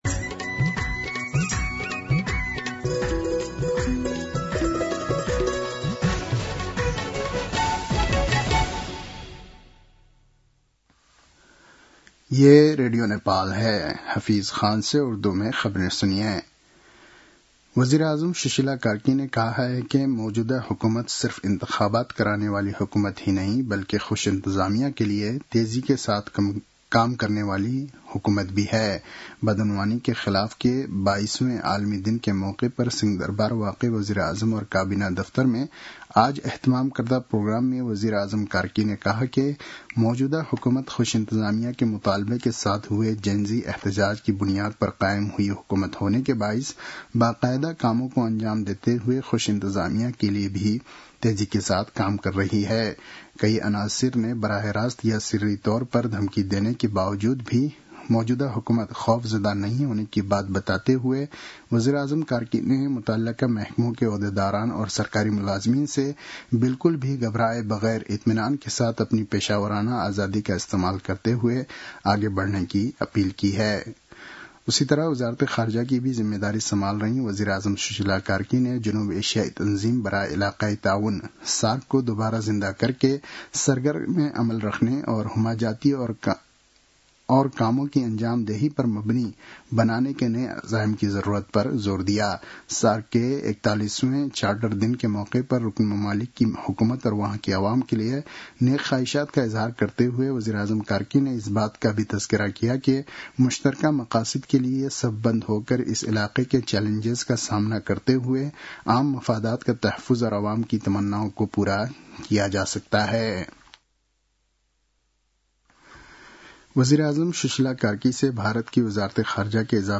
An online outlet of Nepal's national radio broadcaster
उर्दु भाषामा समाचार : २३ मंसिर , २०८२